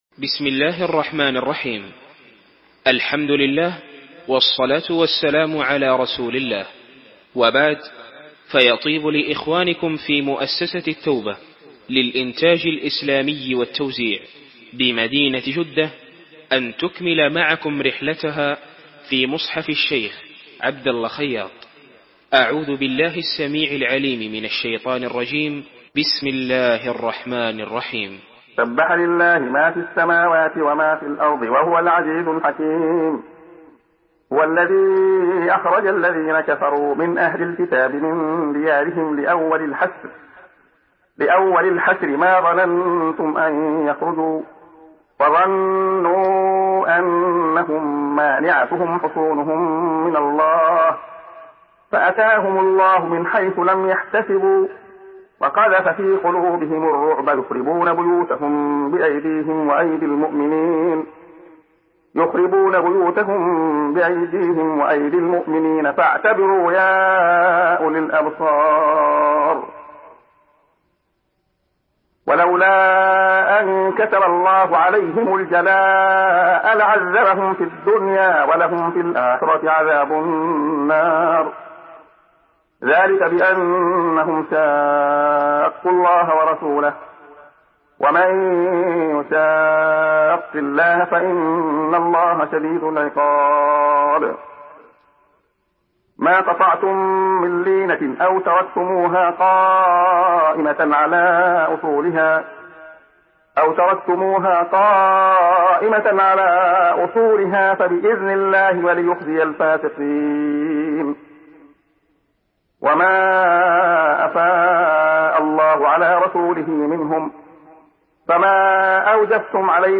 Surah Hasr MP3 by Abdullah Khayyat in Hafs An Asim narration.
Murattal